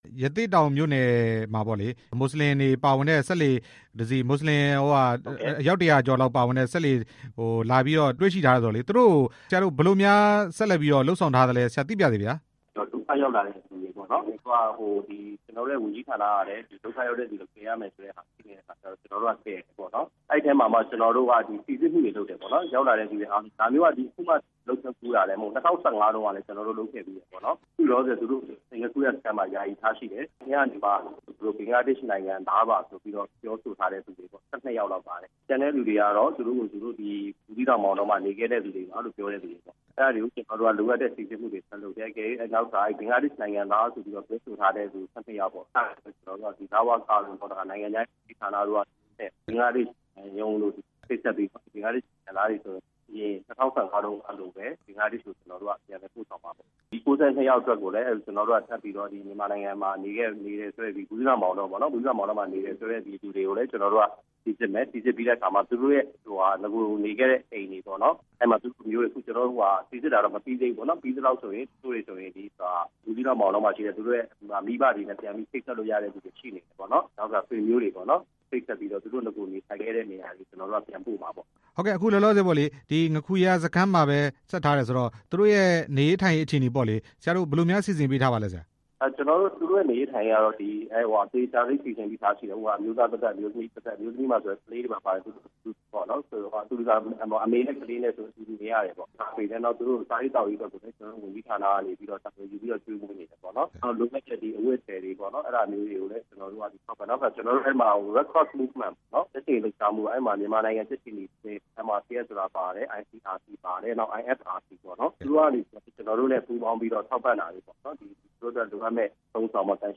ငါးခူရစခန်းမှာ စိစစ်နေတဲ့ မွတ်စလင် ၁၀၄ ဦးအကြောင်း မေးမြန်းချက်